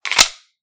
Gun sounds
gun_magazine_insert_empty_4.ogg